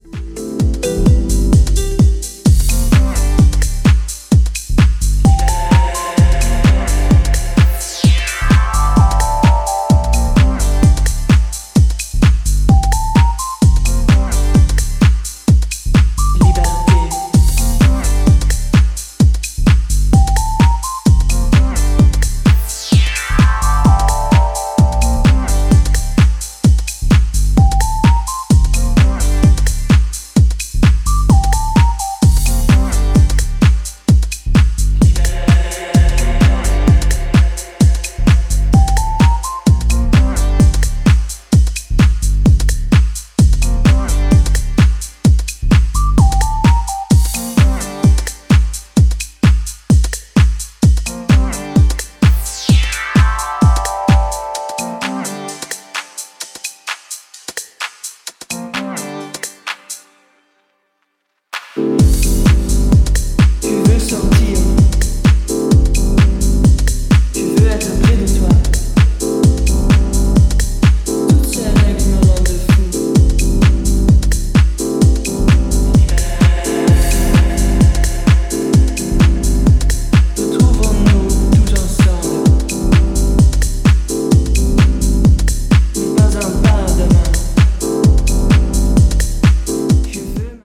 ジャンル(スタイル) DEEP HOUSE / TECH HOUSE